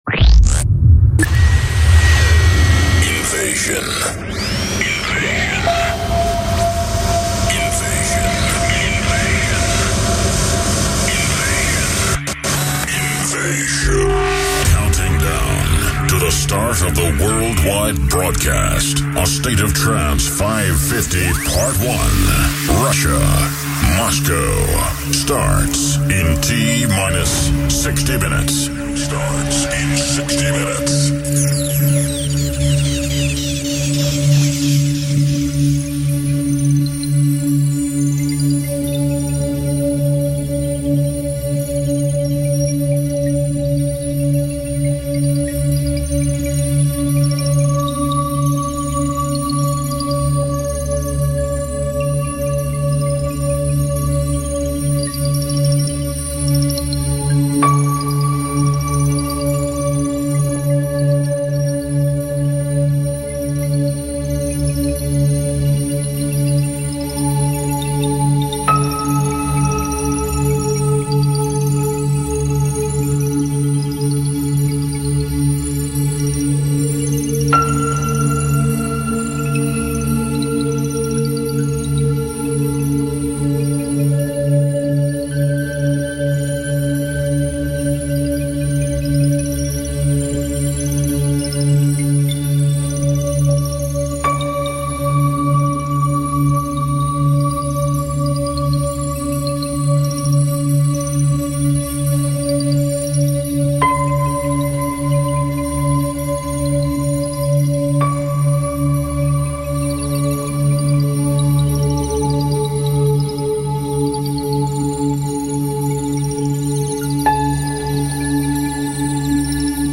Warmup Set